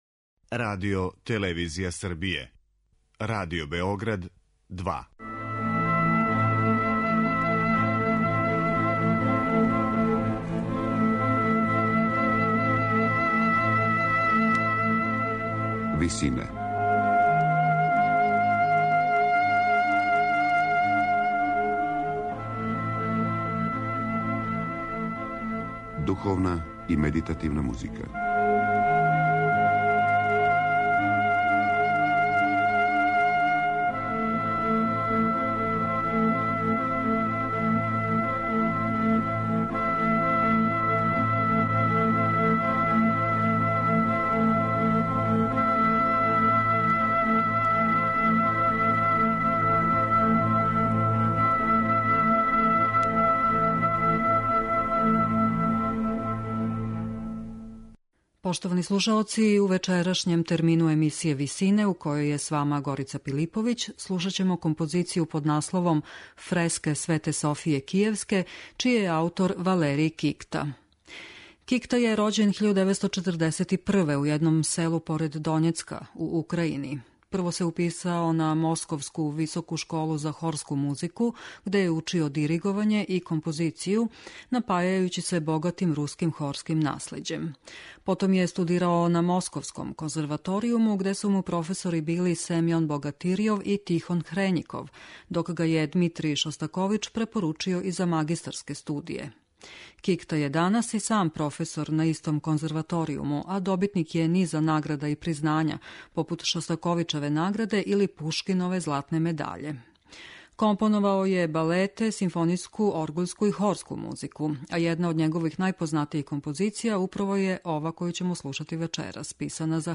медитативне и духовне композиције
дело за харфу и оркестар